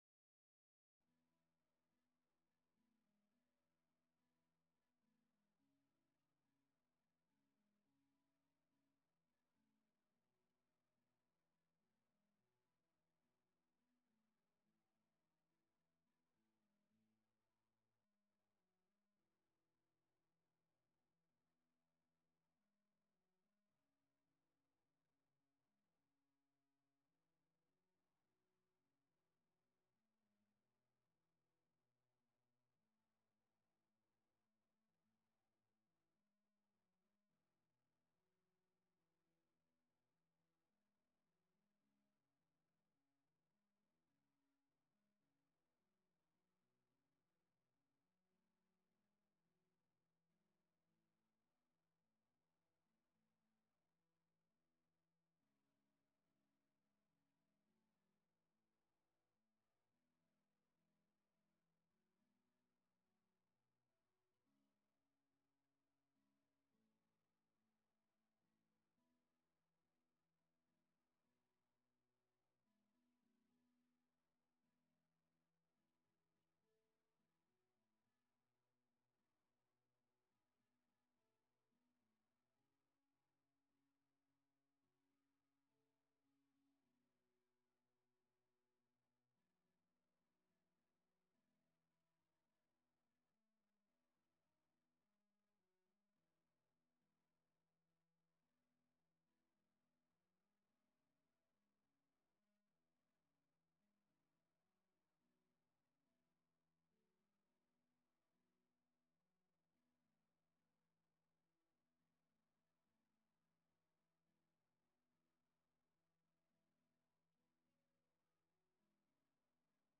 Guitar Quartet – Intermediate Level
MIDI Mockup Recording